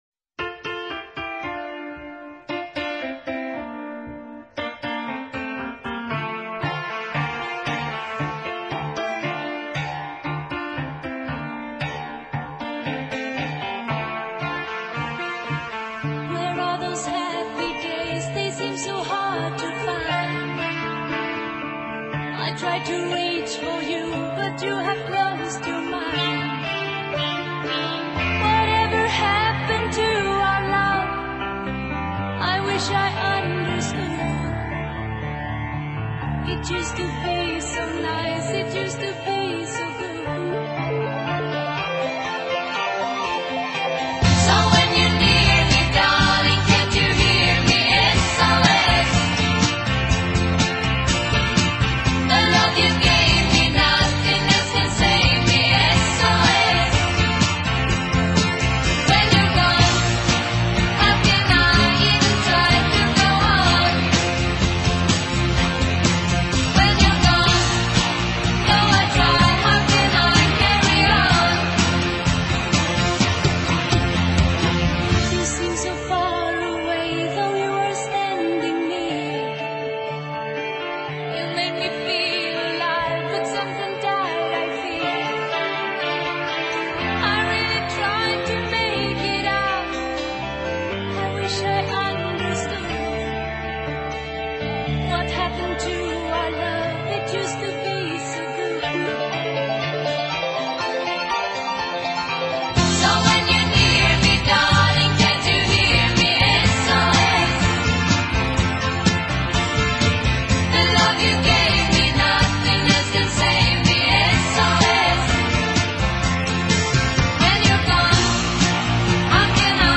音乐风格： 流行|流行/摇滚|(Pop/Rock)